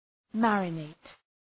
Shkrimi fonetik {‘mærə,neıt}